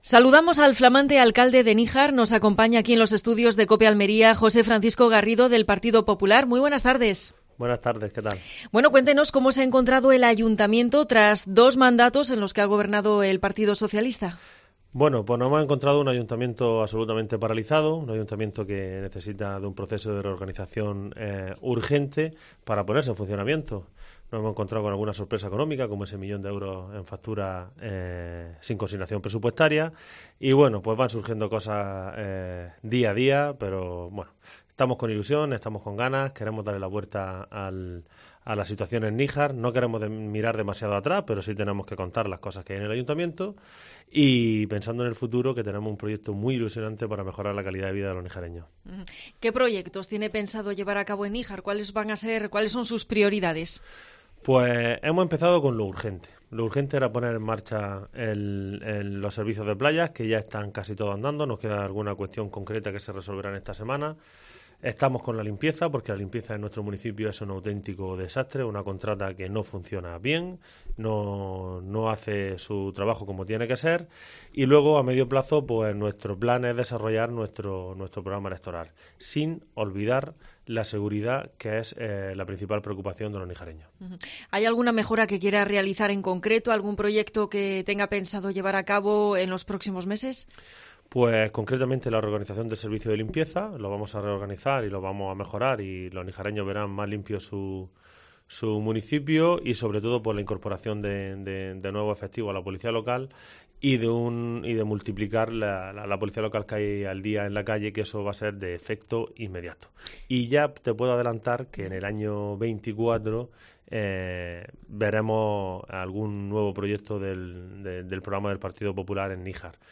Entrevista al alcalde de Níjar, José Francisco Garrido